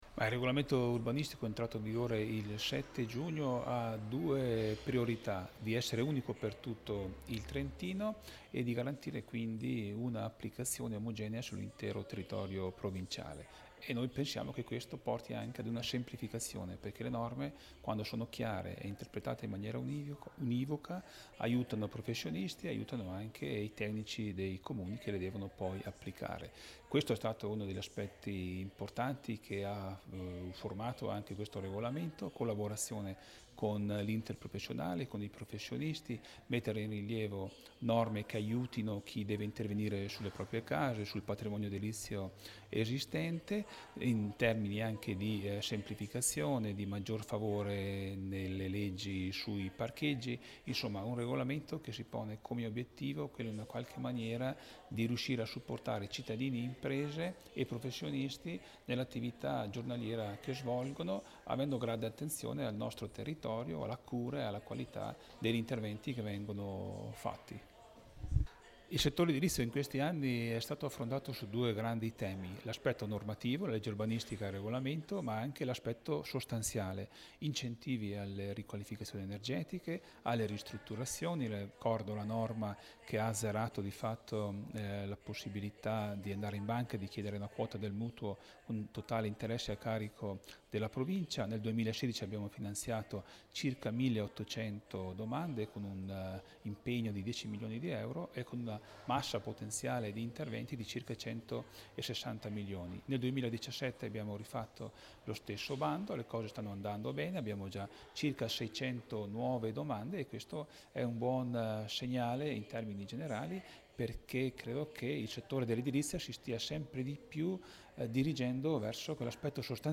Il presidente Ugo Rossi e l’assessore Carlo Daldoss hanno partecipato stamani al primo incontro pubblico di presentazione del nuovo regolamento urbanistico - edilizio